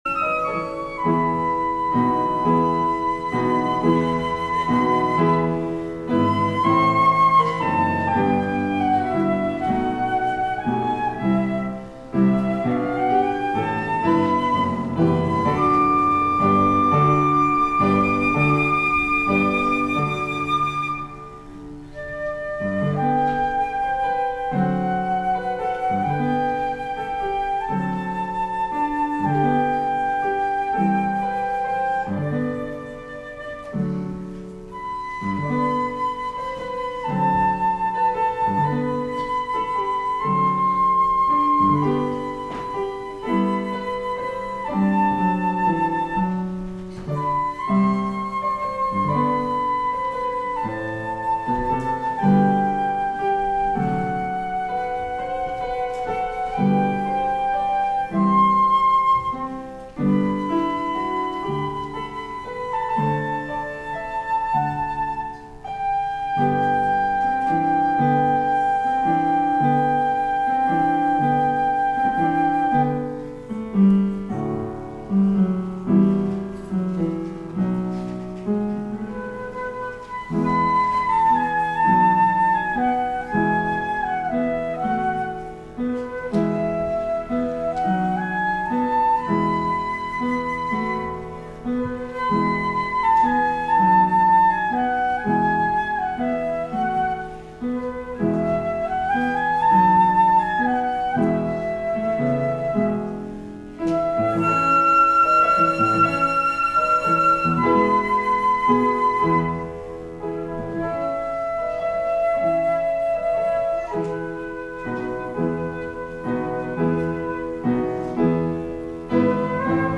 Here is a recording of our service of Lessons and Carols, held at 8:00 PM.